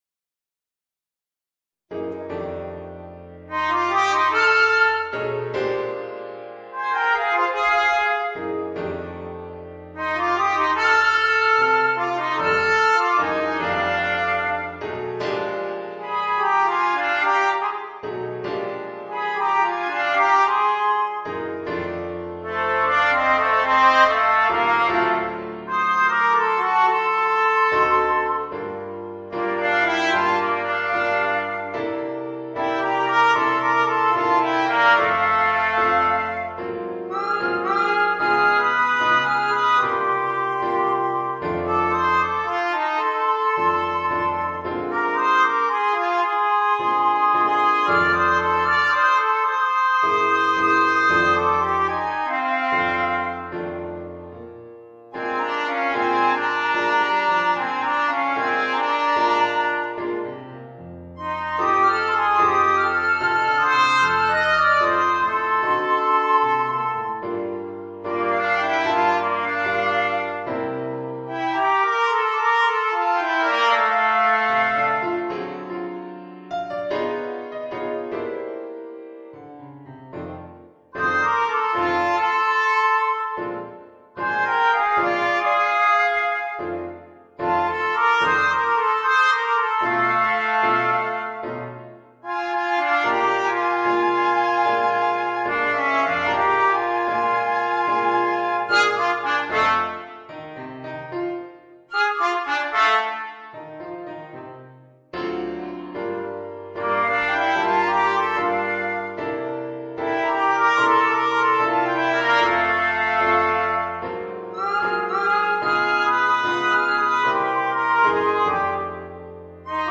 Voicing: Flute, Trumpet and Piano